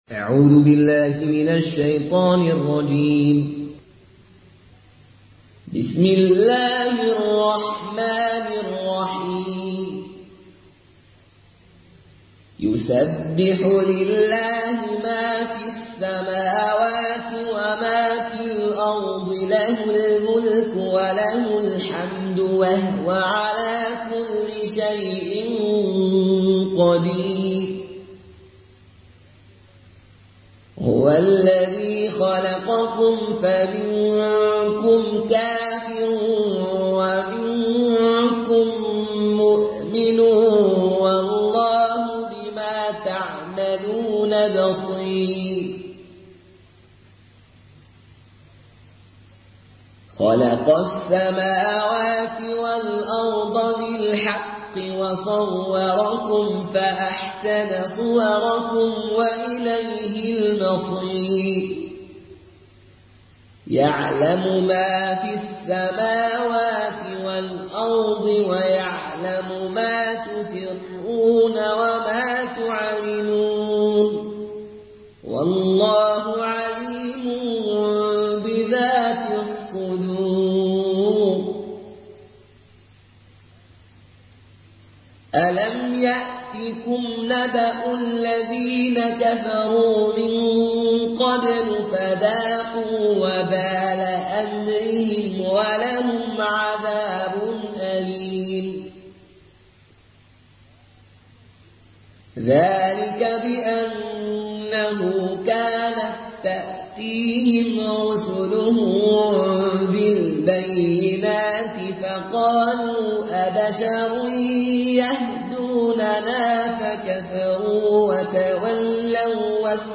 Une récitation touchante et belle des versets coraniques par la narration Qaloon An Nafi.
Murattal Qaloon An Nafi